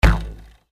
Here's a few pics from a recent audio capture session I did up in the North woods of Wisconsin.
Tools of the Trade: Trusty PowerBook, Rode NTG-2, Sennheiser HD-285, and various implements of noise making.
Not since I was a wee lad have I had cause to bust out the old bow and arrow, was good to get it out and put it through it's paces.
BowRelease01.mp3